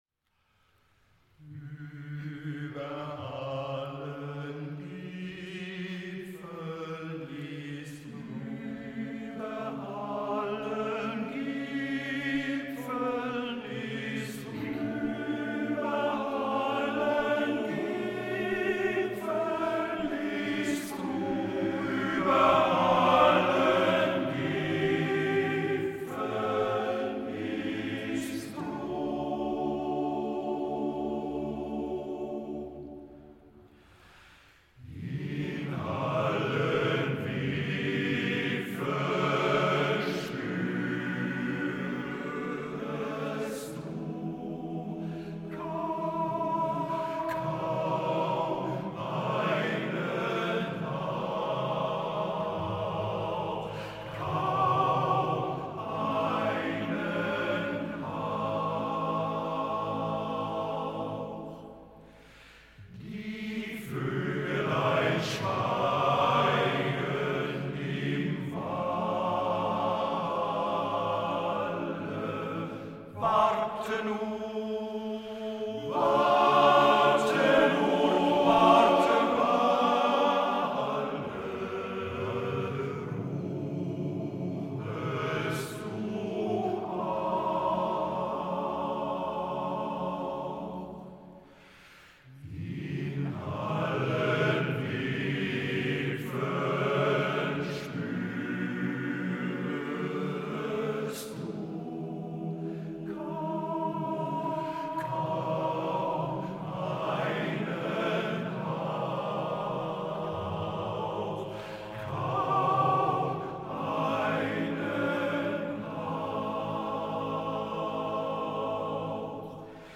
Unsere Wettbewerbsstücke vom Männerchorwettbewerb 2022 in Horbach: